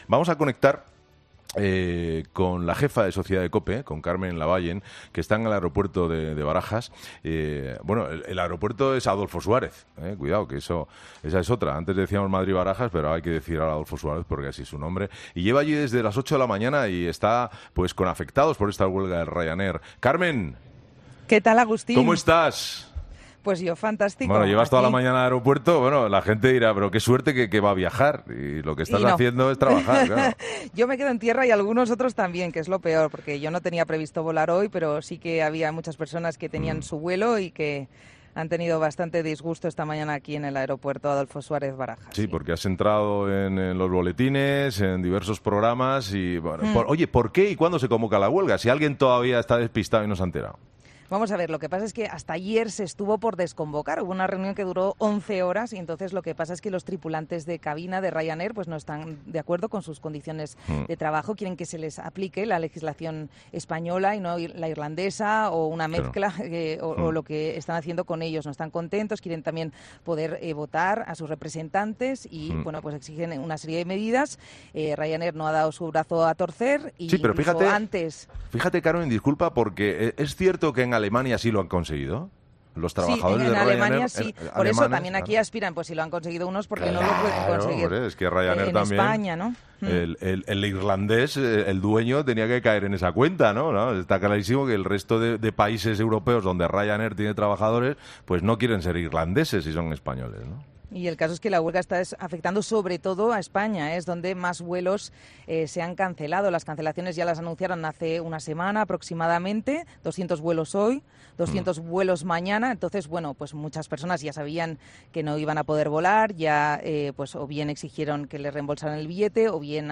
Una afectada por la huelga nos cuenta su experiencia en Herrera en Cope